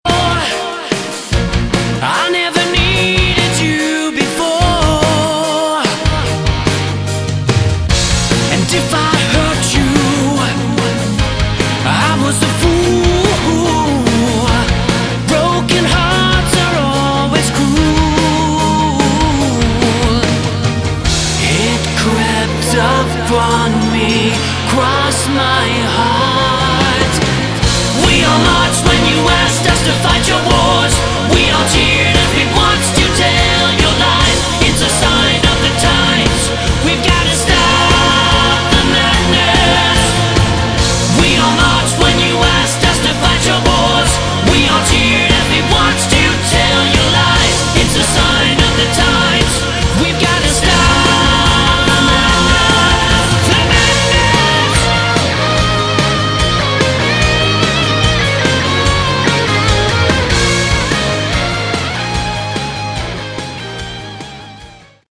Category: Hard Rock
vocals, lead & Rythm guitars
keyboards, acoustic & Clean guitars
drums, keyboards
bass/Fretless bass